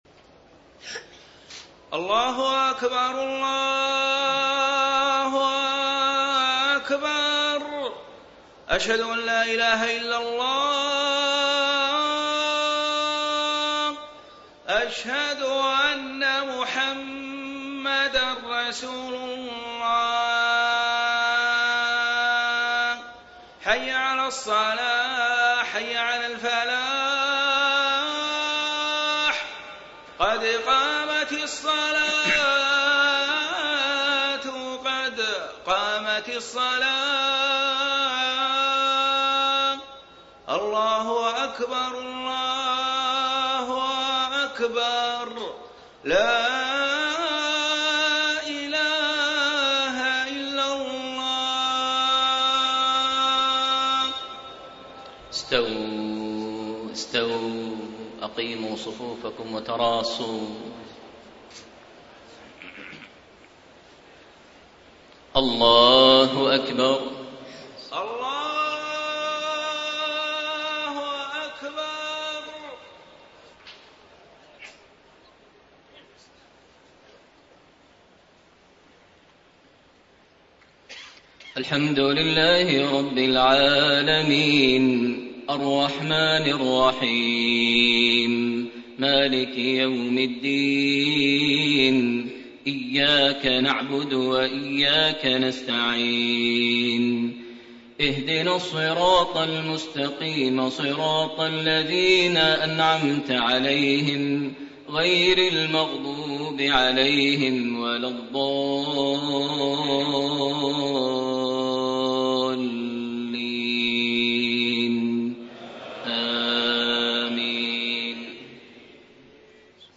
صلاة العشاء 9-5-1435 ما تيسرمن سورة ال عمران > 1435 🕋 > الفروض - تلاوات الحرمين